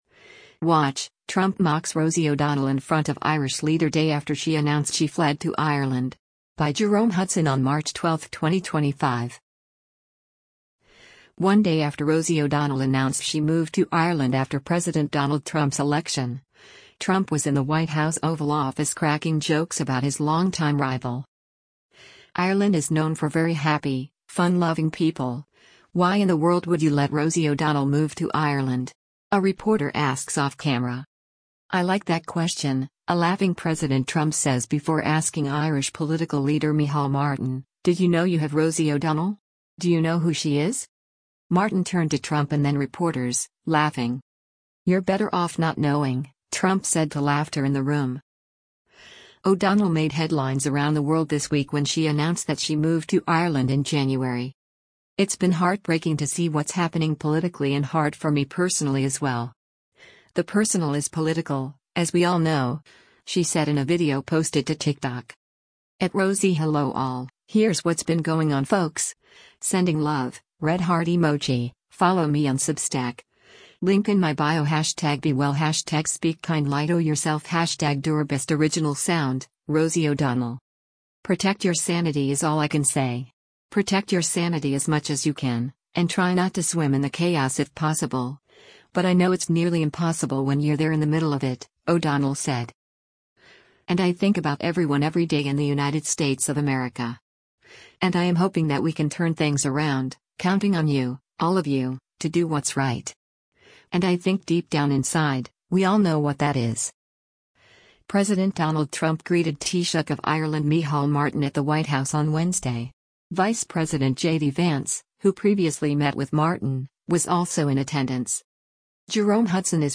One day after Rosie O’Donnell announced she moved to Ireland after President Donald Trump’s election, Trump was in the White House Oval Office cracking jokes about his longtime rival.
Martin turned to Trump and then reporters, laughing.
“You’re better off not knowing,” Trump said to laughter in the room.